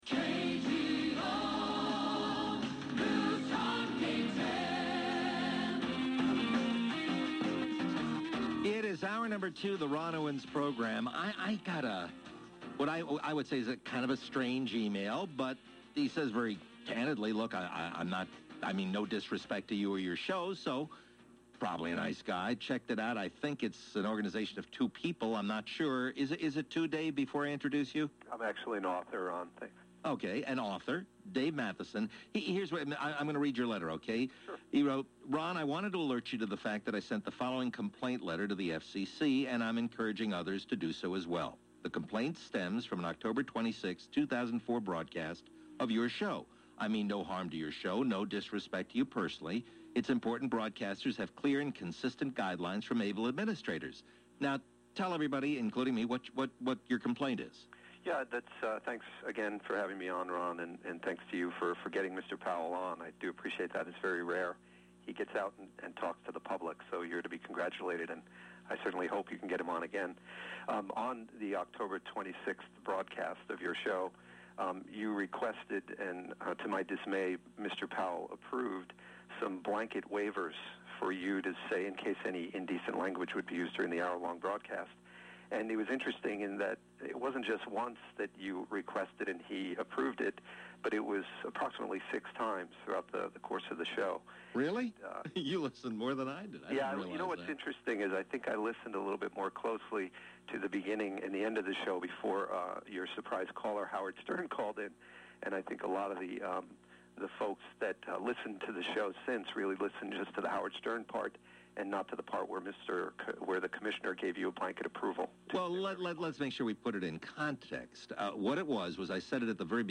Radio Interviews: